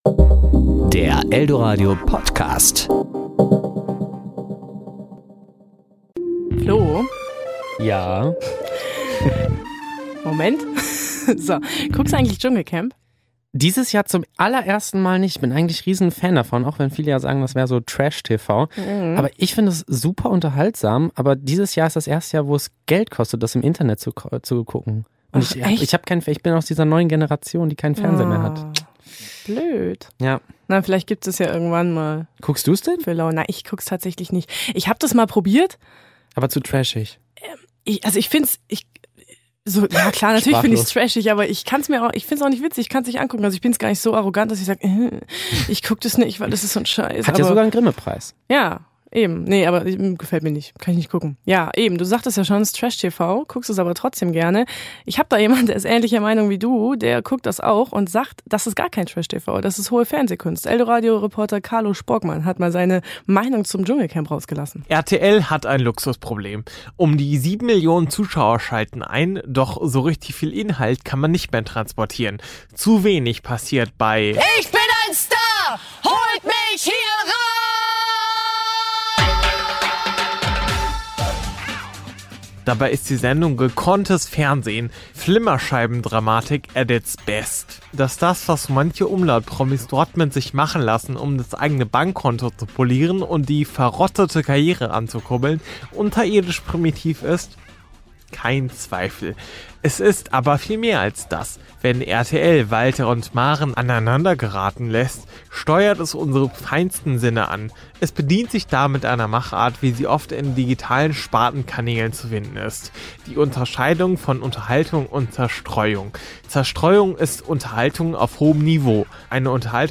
Serie: Beiträge  Ressort: Wort